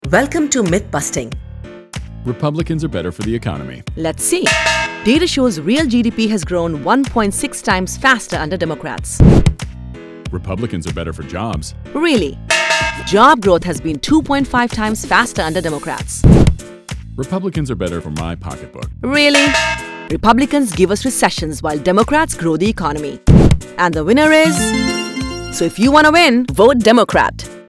She can modulate her voice to be serious, cheeky, matter-of-fact, motivational, exhorting or whatever else the content demands.
A LITTLE SECRET - Did you know that using a TransAtlantic voice (not too American, not too British, but a bit of both) perks up the ears of the listener helping your message stand out from the crowd?